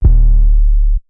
Dro 808 1.wav